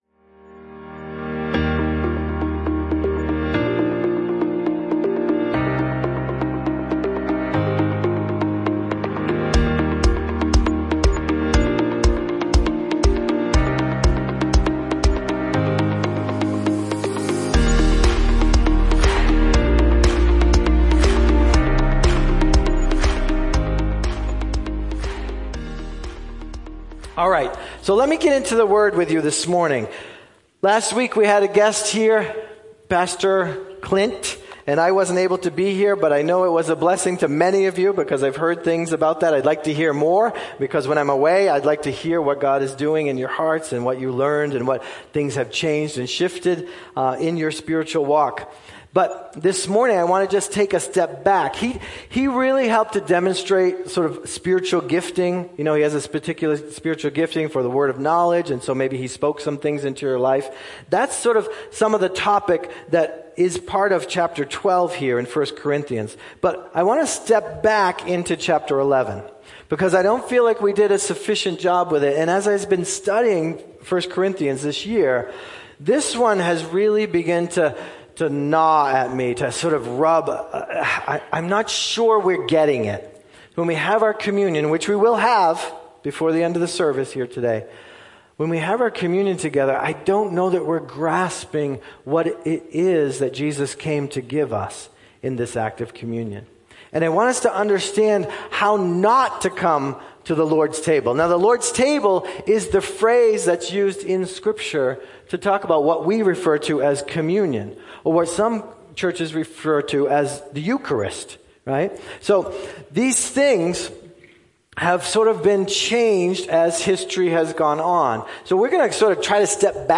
Holden Chapel Sermons